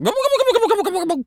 pgs/Assets/Audio/Animal_Impersonations/turkey_ostrich_gobble_02.wav at master
turkey_ostrich_gobble_02.wav